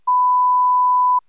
etttusen.wav